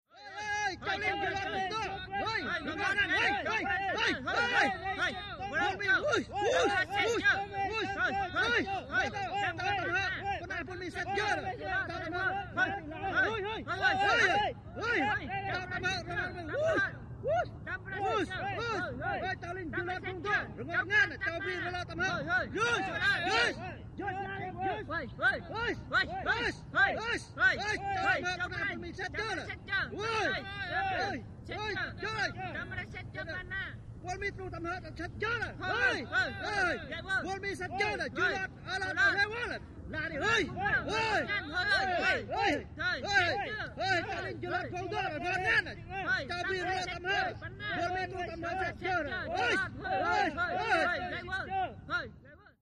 Troop Activity: ( Foreign ) Vietnamese Male Group Yells. Excited Or Upset. Birds And Planes In Distant Background.